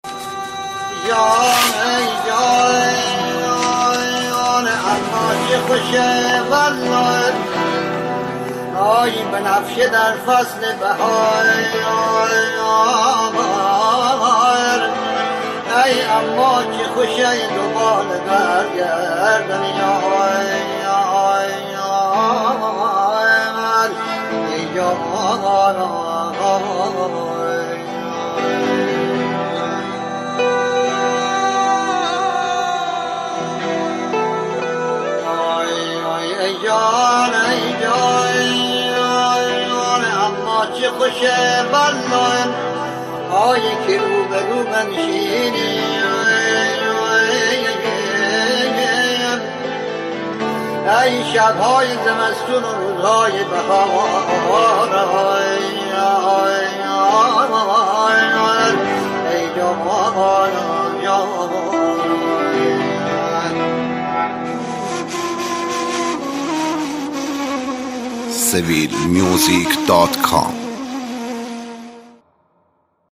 ژانر: پاپ